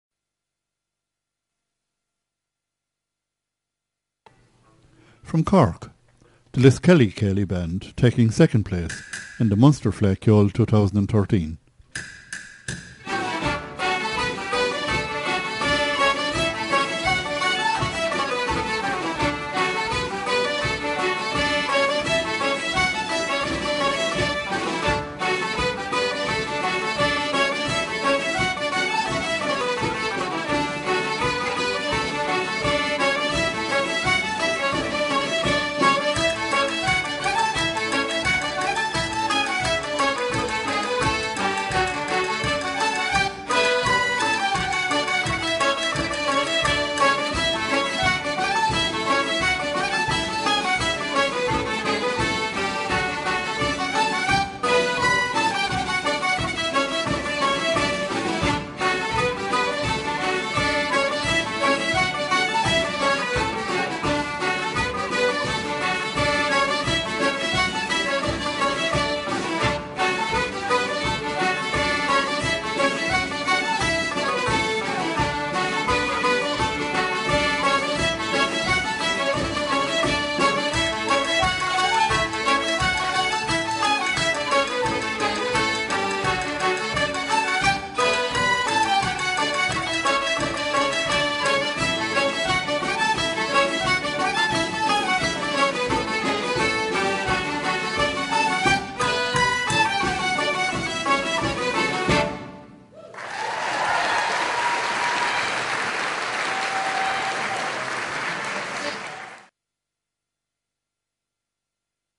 Taking second pace in the Under-18 Céili Band Competition at Munster Fleadh Cheoil 2013 in University of Limerick, Liskelly Céili Band, Co. Cork, play a hornpipe - “Down by the Glen”.
Liskelly Céili Band
Fleadh, Céilí Band, Hornpipe, ComhaltasLive